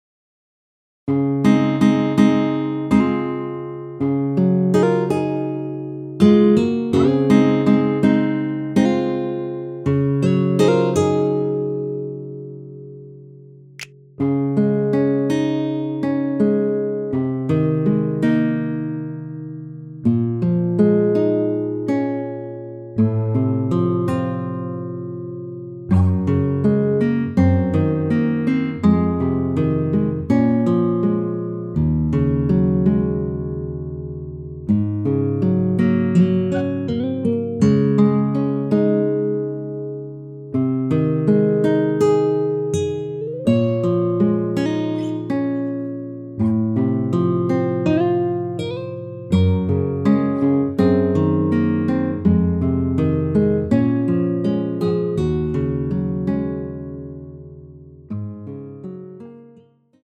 원키에서(-4)내린 MR입니다.
Db
앞부분30초, 뒷부분30초씩 편집해서 올려 드리고 있습니다.